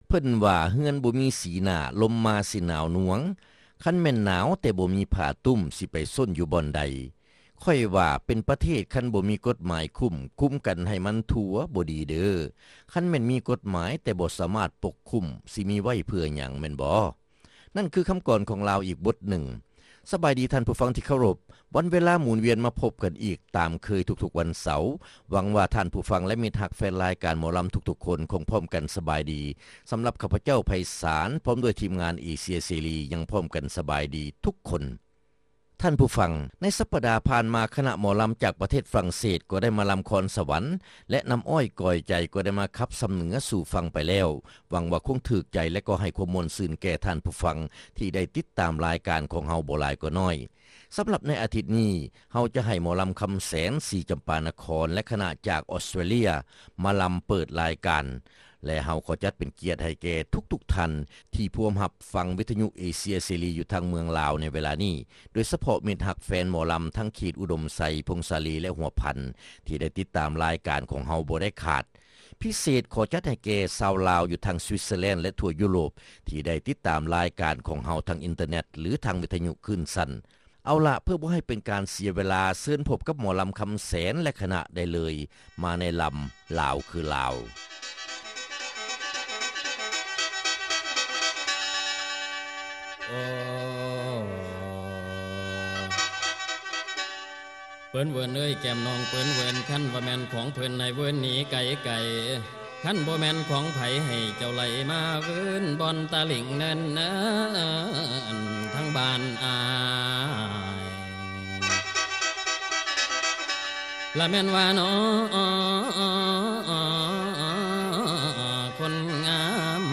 ຣາຍການໜໍລຳ ປະຈຳສັປະດາ ວັນທີ 15 ເດືອນ ທັນວາ ປີ 2006